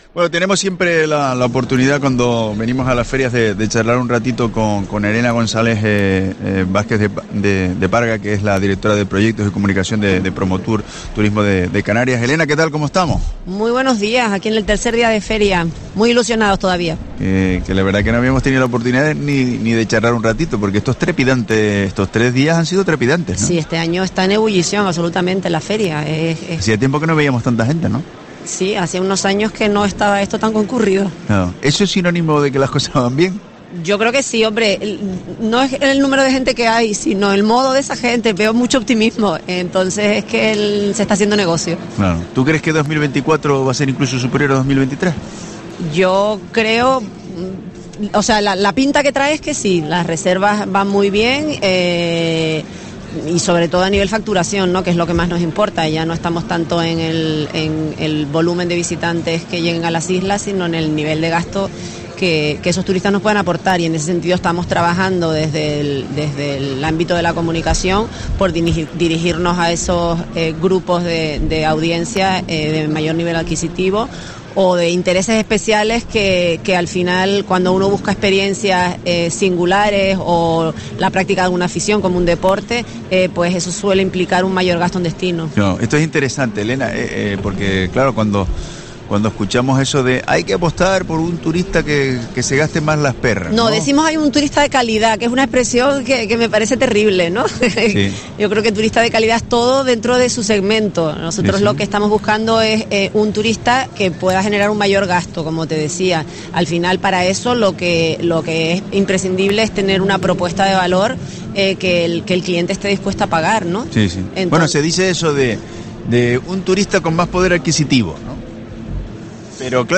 Entrevista
en FITUR 2024